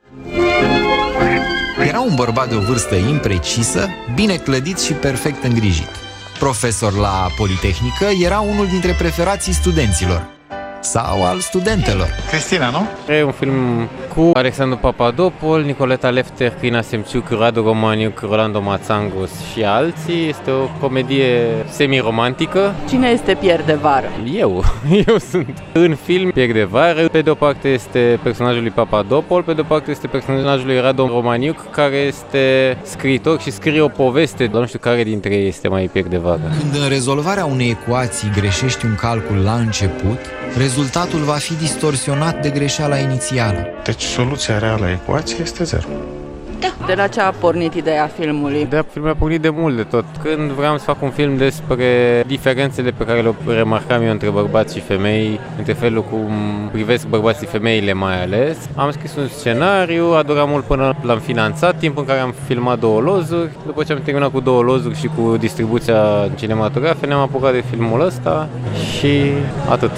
l-a intervievat pe Paul Negoescu: